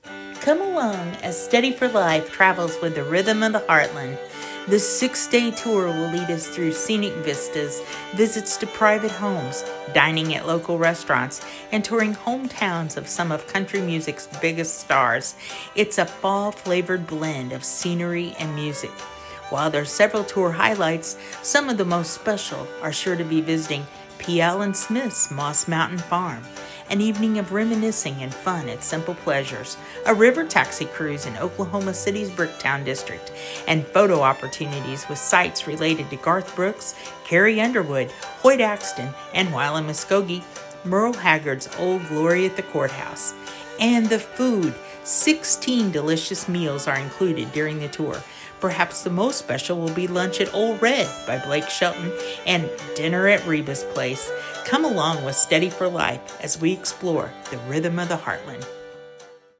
6.-Oct-25-oklahoma-and-arkansas-with-music.mp3